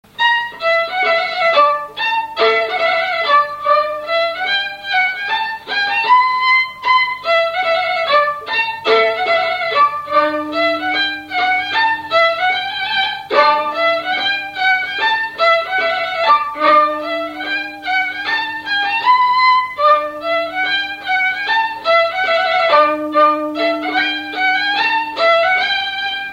Scottish
Saint-Hilaire-de-Chaléons
Résumé instrumental
Pièce musicale inédite